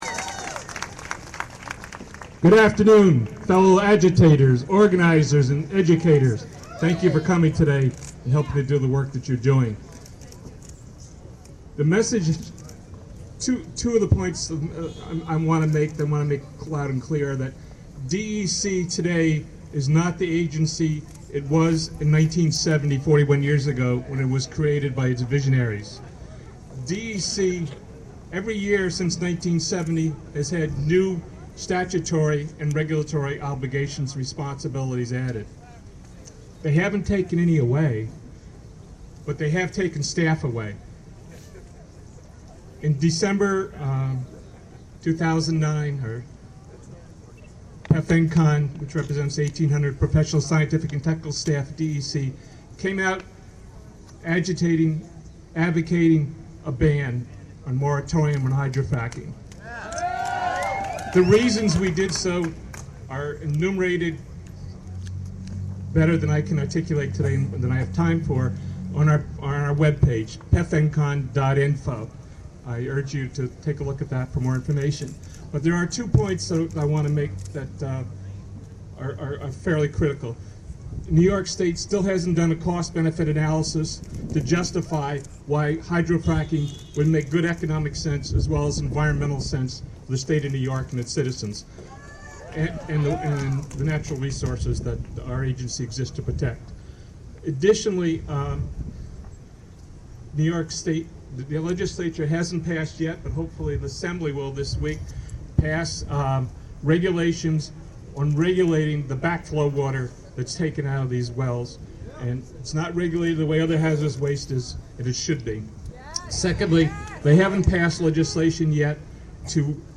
speaking at Earth Day Albany rally May 2, 2011.